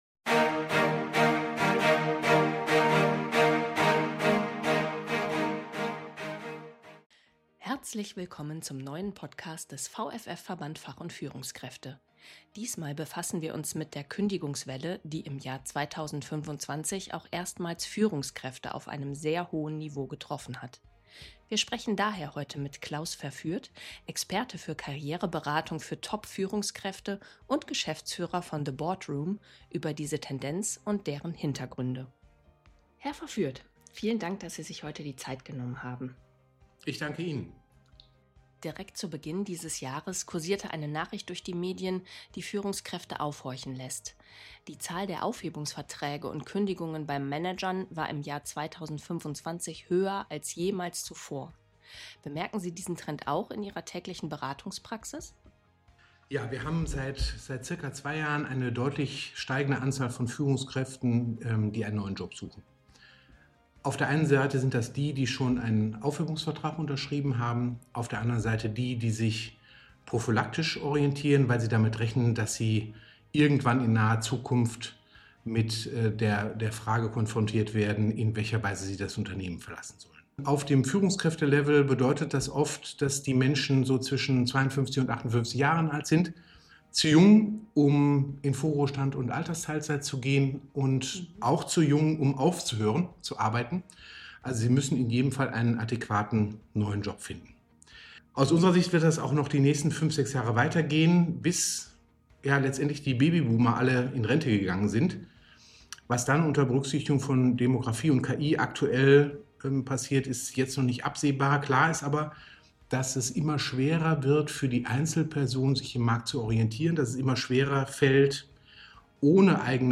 Podcast-Interview